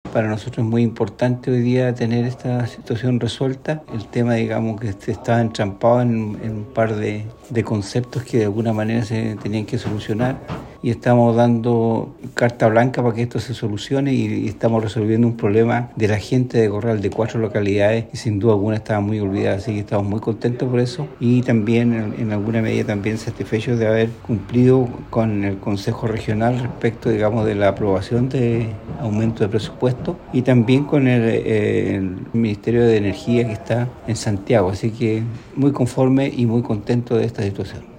El Alcalde de Corral, Miguel Hernández Mella, expresó su satisfacción por este logro, resaltando el esfuerzo y la dedicación de su equipo para superar los desafíos presentados durante el proceso.
AUDIO-ALCALDE.mp3